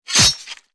dig.wav